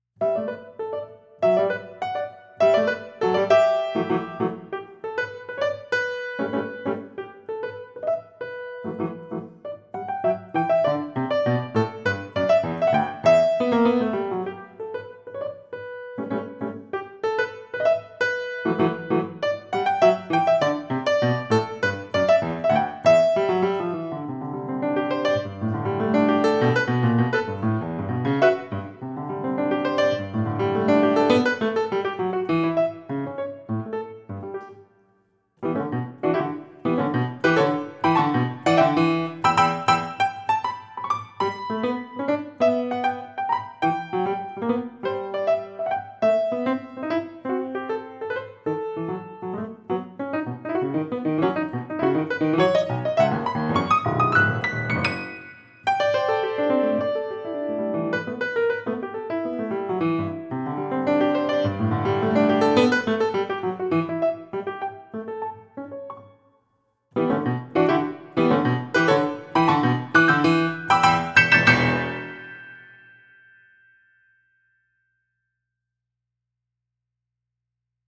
El huayno destaca como el género musical propio de la zona andina del Perú.
Sin embargo, “En las alturas” es una creación adaptada al idioma del piano, comprendiendo sus posibilidades y limitaciones en comparación con los instrumentos antes mencionados.